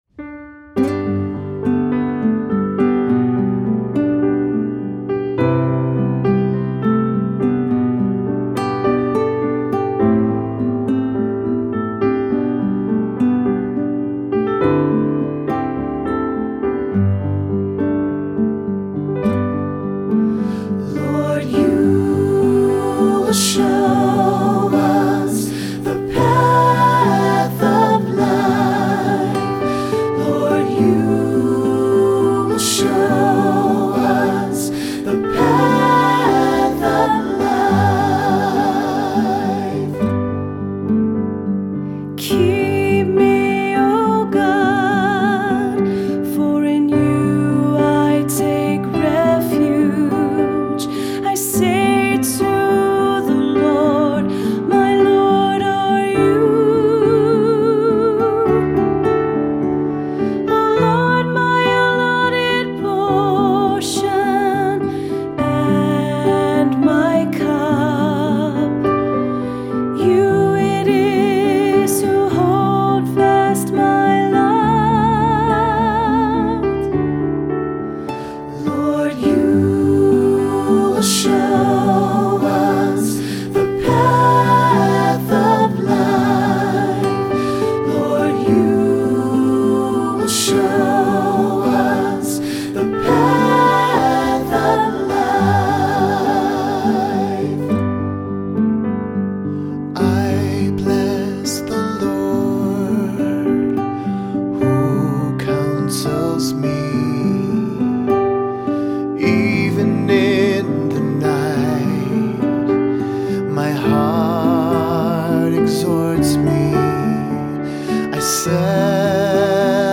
Voicing: Assembly,SATB, cantor